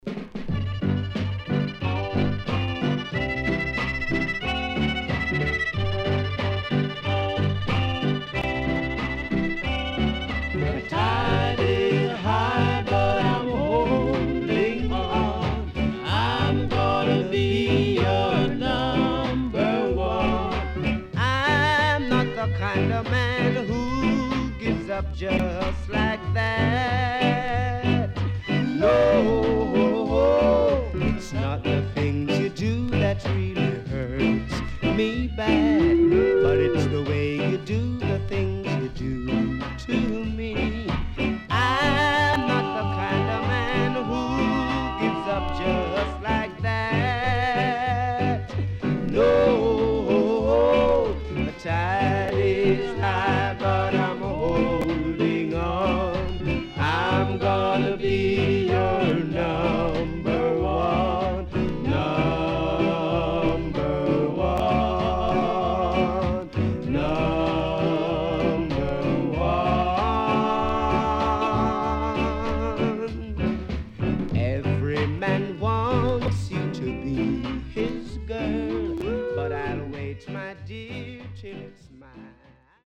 SIDE A:所々チリノイズ、プチノイズ入ります。